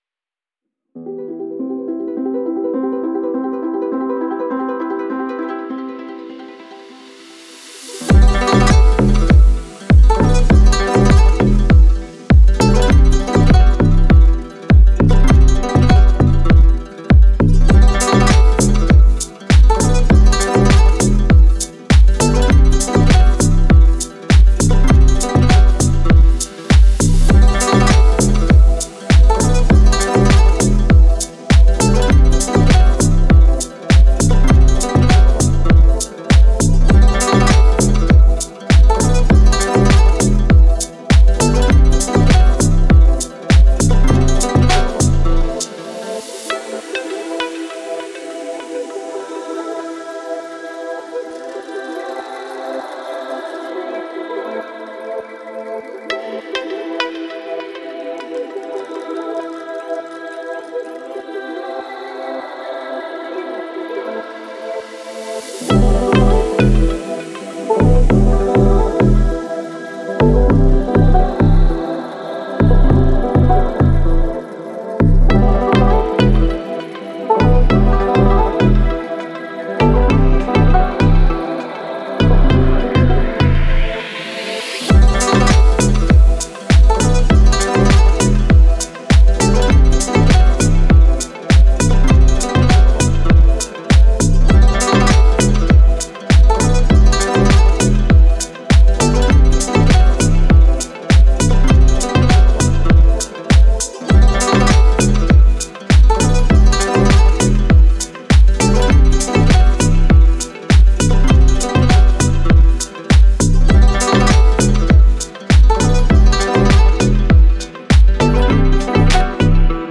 это мощное произведение в жанре альтернативного рока